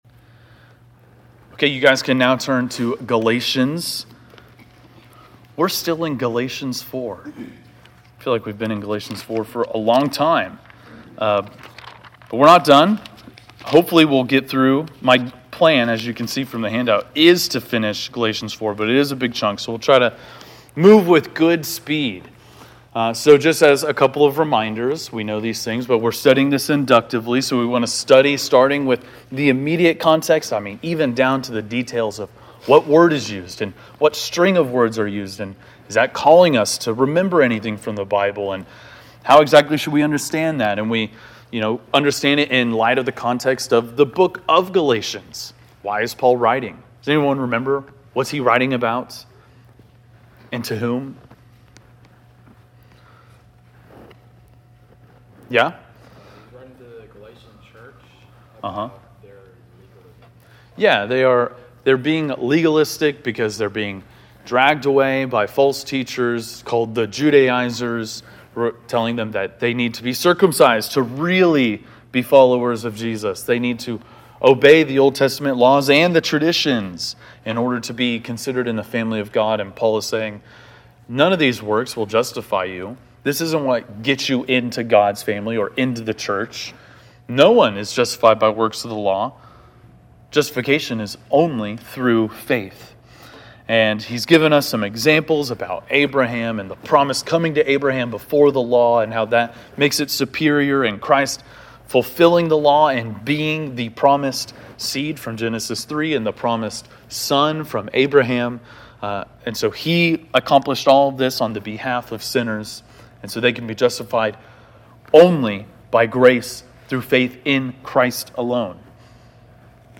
Galatians 4:21-5:1 (Inductive Bible Study)